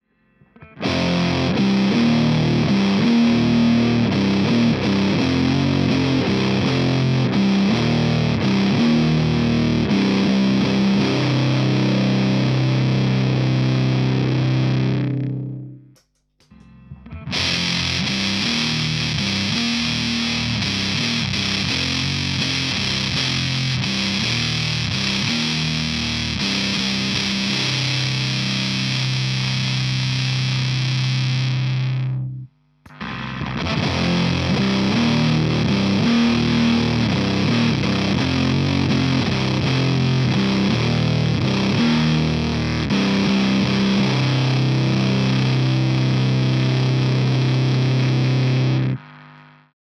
heißt er auch anders Kommt mit einem Boost, einem Muffy (ha!) und einer Ratte daher.
Kette war: DI -> Reampbox -> PoD Pedal -> Combo-Amp 12" -> Mic -> DAW (bei Zimmerlautstärke, keine Prozessierung) jeweils bei beiden Files die Reihenfolge -> nur Distortion -> nur Fuzz -> Fuzz und Distortion